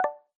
blip.mp3